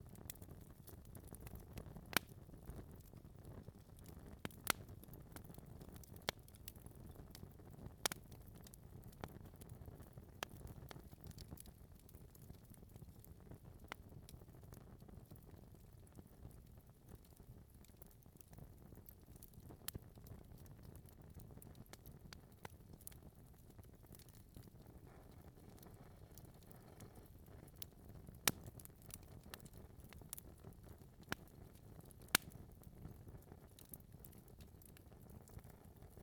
fireplace snappy.ogg